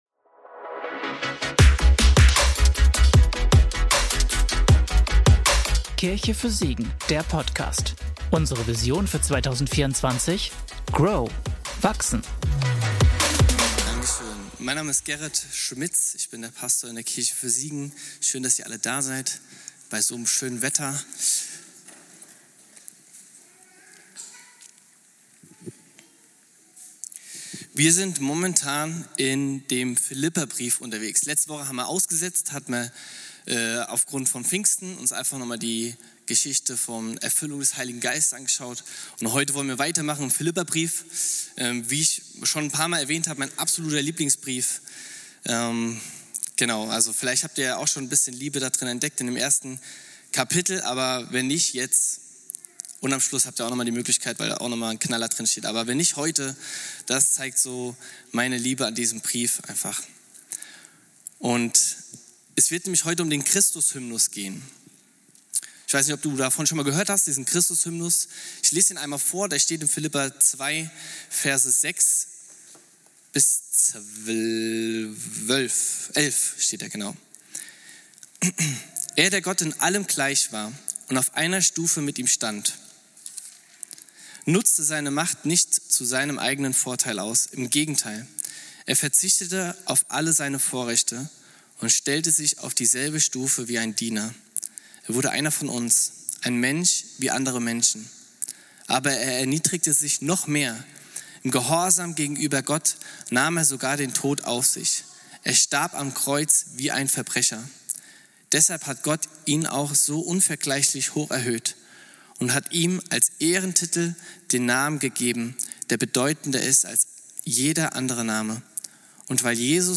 Der Christushymnus, Paulus benutzt ein Lied um die Person Jesus zu veranschaulichen. Was hat das für eine Relevanz, dass Jesus ein Mensch war und das er den Ehrentitel von Gott erhält. In der Predigt wollen wir uns den Christushymnus anschauen und darin 5 Aussagen über Jesus entdecken.